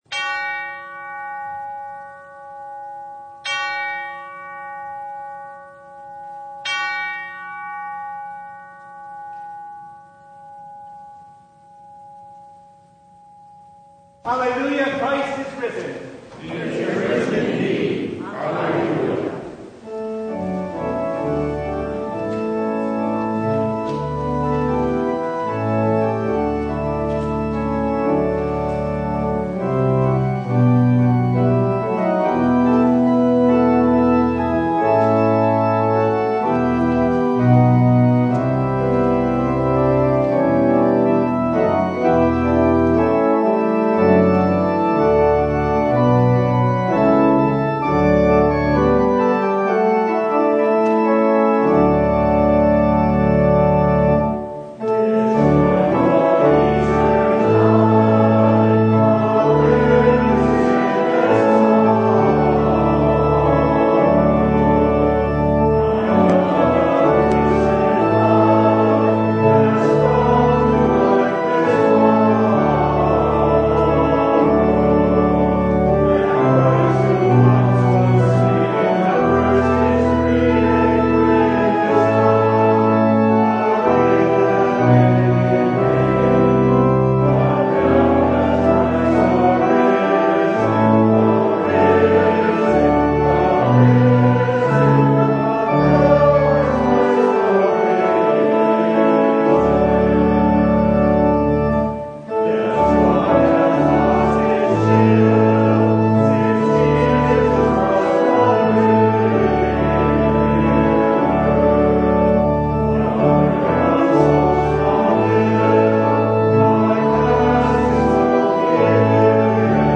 Download Files Notes Bulletin Topics: Full Service « Living by Faith What About the Body?